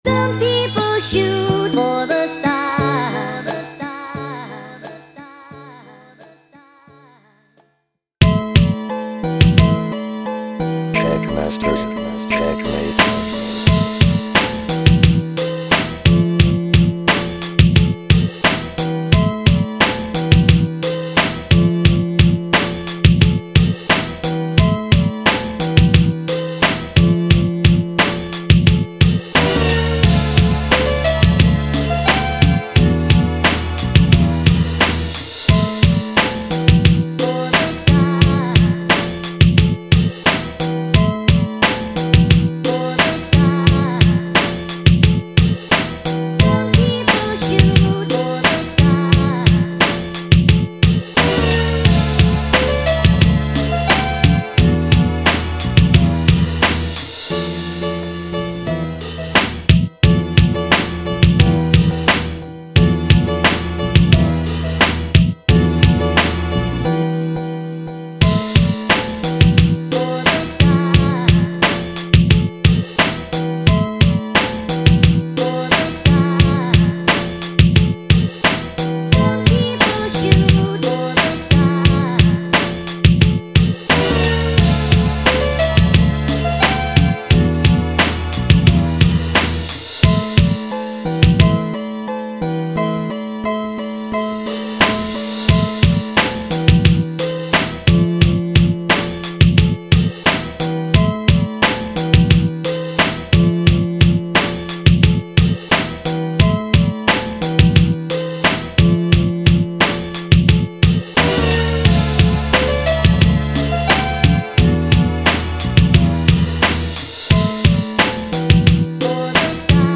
샘플링 한 열곡 했으니 그만하고 얼른 시퀀싱 잡아야죠 ㅋ
2. 변주는 브릿지이고, 그 이후 8마디는사비로 생각했기 때문에
2. 그리고 비트에 따라 잘 집중하다가도, 58초~1분8초 그 부분에서 뜨는 느낌을 지울수가 없네요!!